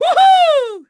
Zafir-Vox_Happy4_kr.wav